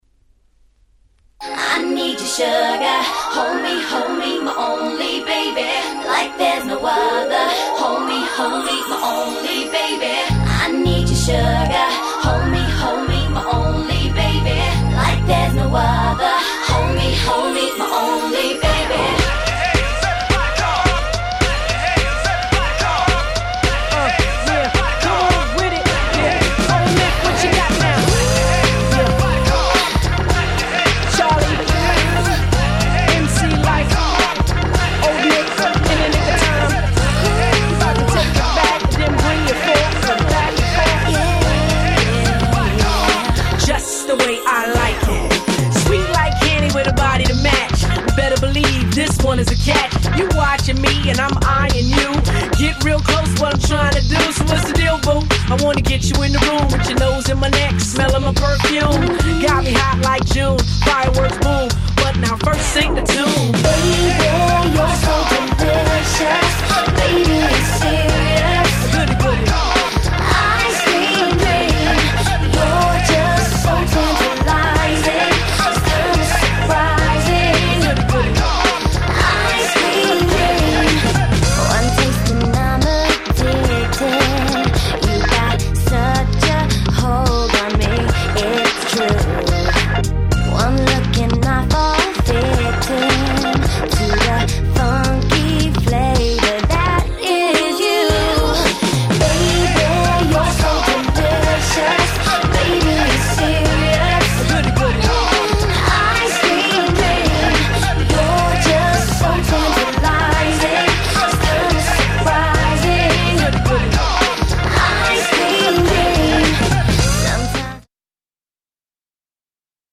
Main Version